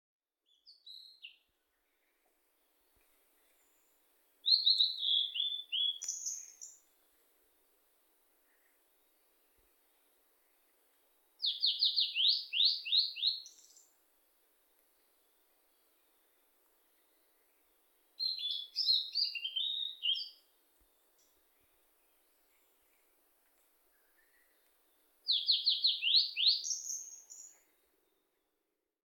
１　オオルリ（大瑠璃）Blue-and-white Flycatcher　全長：17㎝
オスは樹木の天辺で俺様のようにさえずる。
【録音①】　2024年5月　埼玉県県民の森